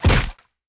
gen_hit5.wav